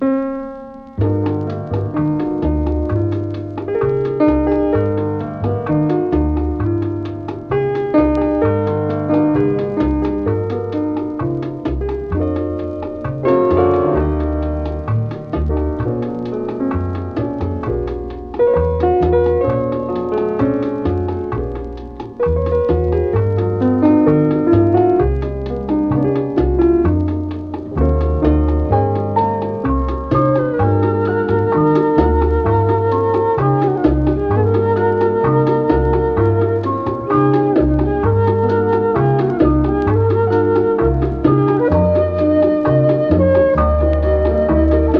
Jazz, Latin, Lounge　USA　12inchレコード　33rpm　Mono